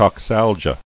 [cox·al·gia]